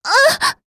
s029_Impact_Hit.wav